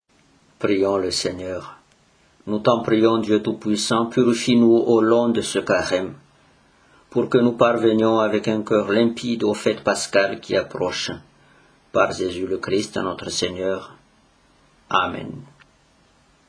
Antienne